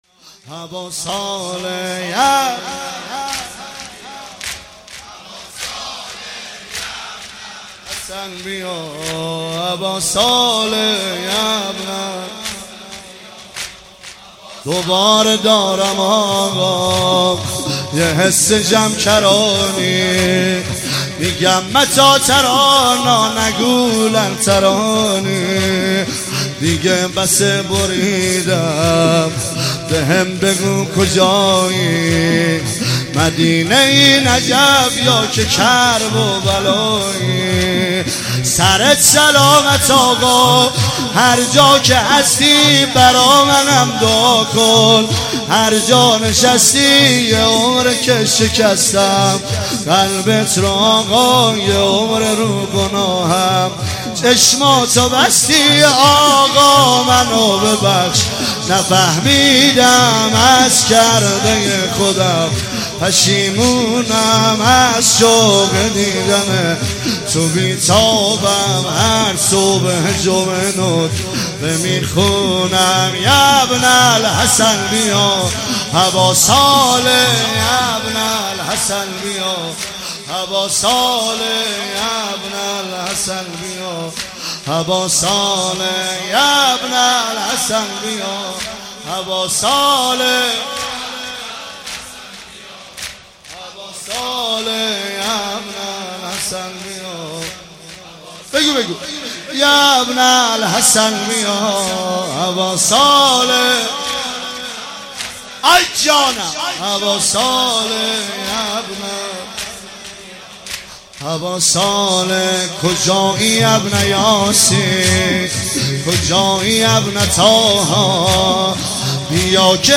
مراسم شب ۲۲ محرم ۱۳۹۶
هیئت حضرت ابوالفضل سرسنگ کاشان